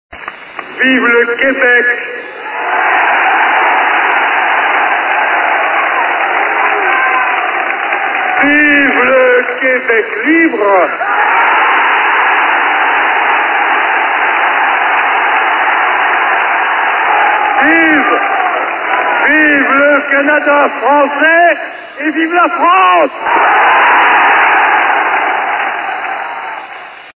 Sur le balcon de l'hôtel de ville, de Gaulle a légitimé la cause de séparation et du FLQ quand il a déclaré «Vive le Québec libre!» (